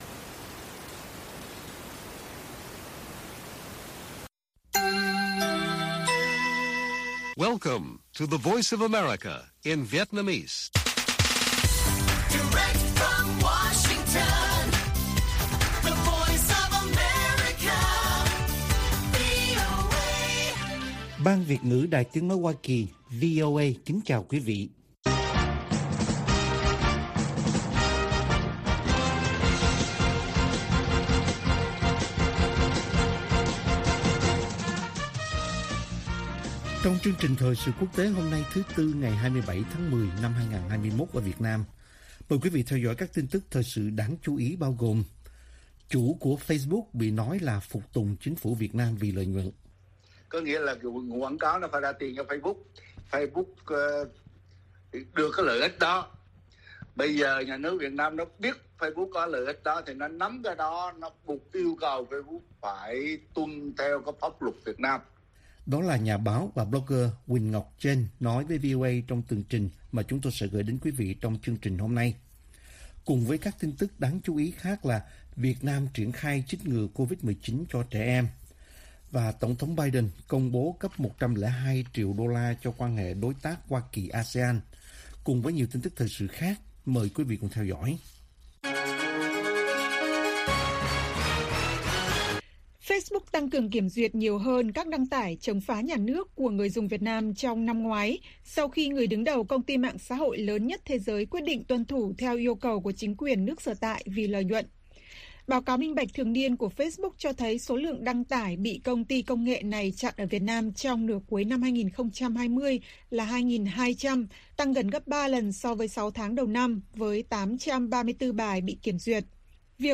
Bản tin VOA ngày 27/10/2021